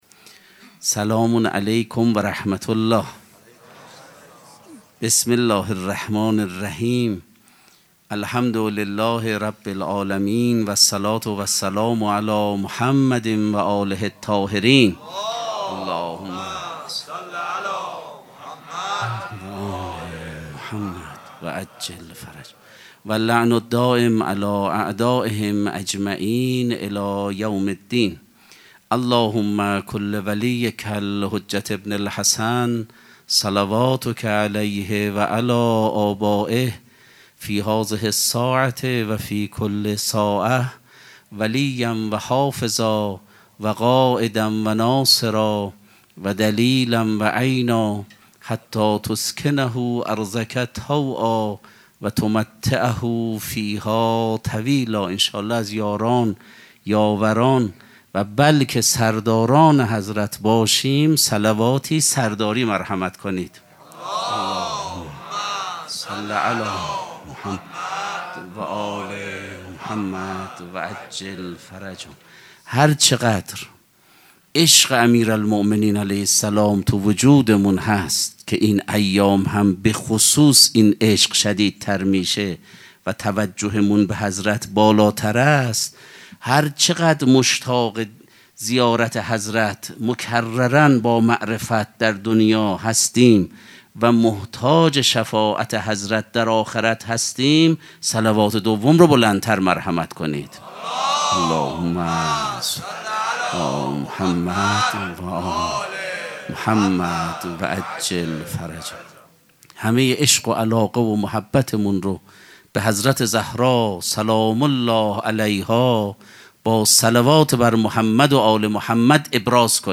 سخنرانی
شب هفتم مراسم هشت شب عاشقی ماه رمضاندوشنبه ۱۳ فروردین ۱۴۰۳ | ۲۱ رمضان ۱۴۴۵‌‌‌‌‌‌‌‌‌‌‌‌‌هیئت ریحانه الحسین سلام الله علیها